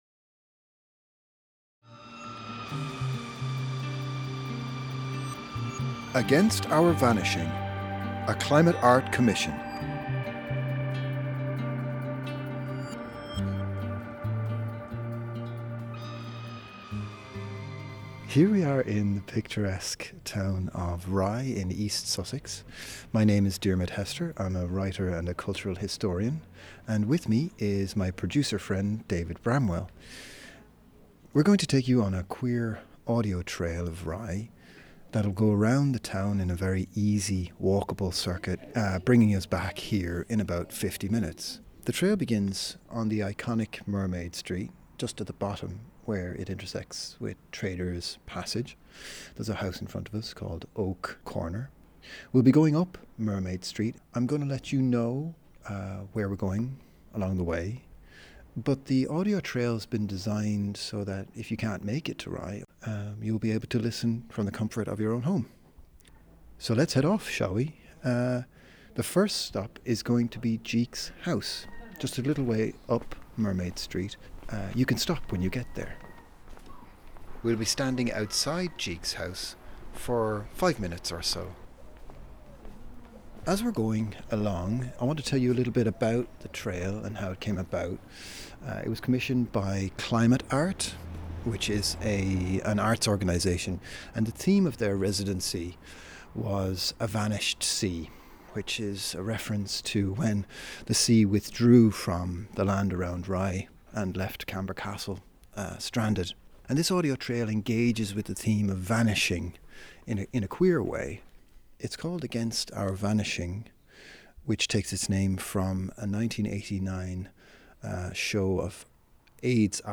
Location: In the town of Rye or anywhere you are
Location: In the town of Rye or anywhere you are Against Our Vanishing is a free audio trail that uncovers the LGBTQ+ history and culture of Rye.